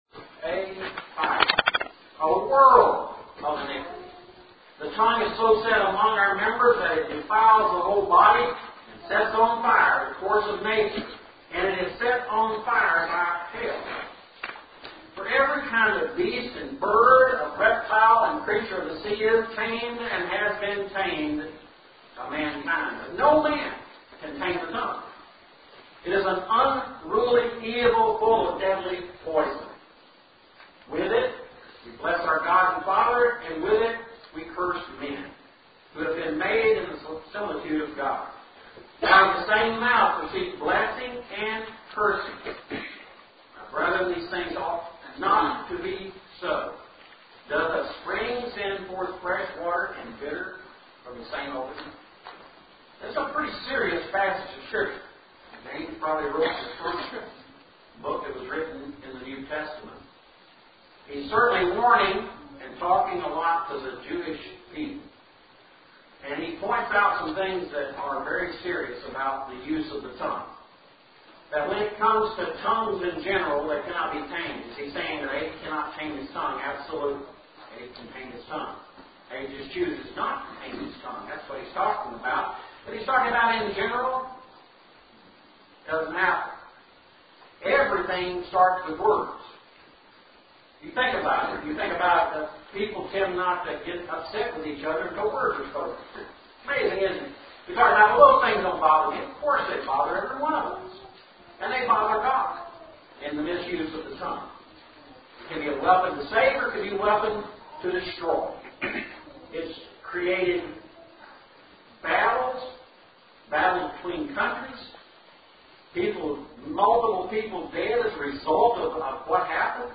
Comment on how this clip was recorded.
Note that the first lesson had mic problems and a back-up mic was used to record the lesson, so quality is from at a distance and is quite poor. Lesson two was recorded normally (with a different mic).